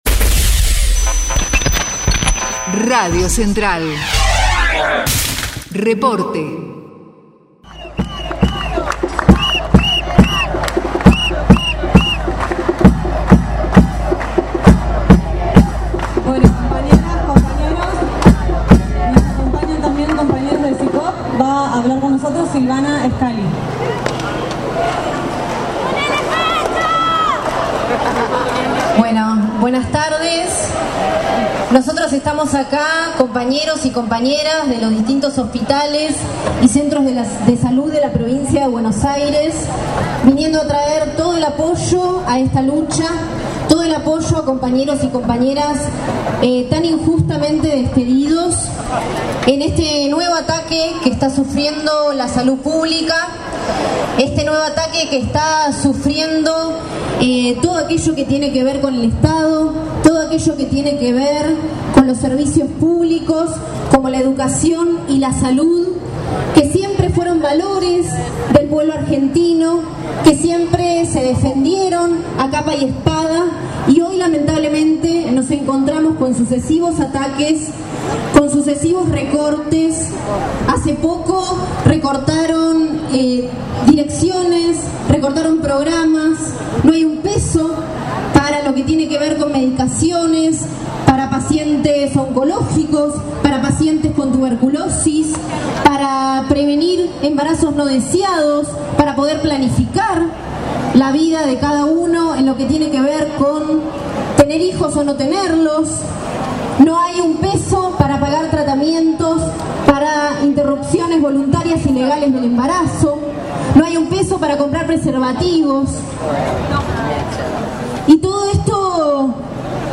ABRAZO AL HOSPITAL "LAURA BONAPARTE" - Testimonio